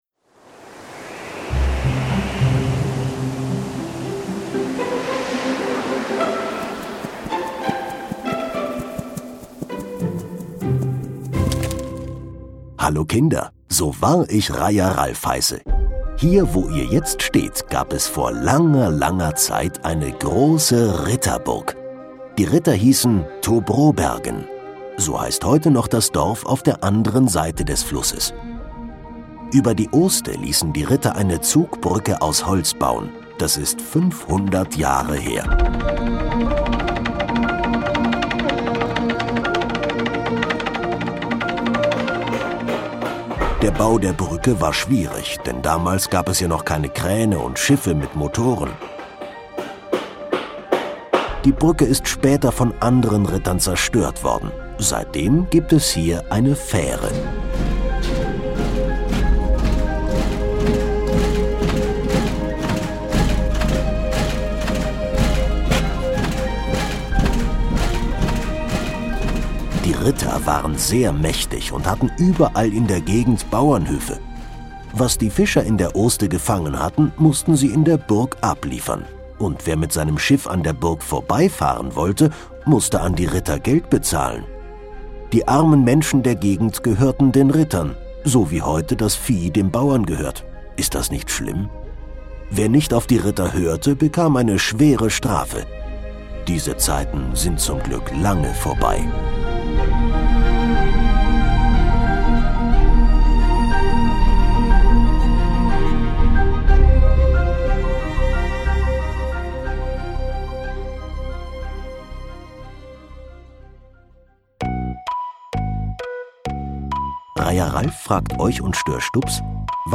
Burg Brobergen - Kinder-Audio-Guide Oste-Natur-Navi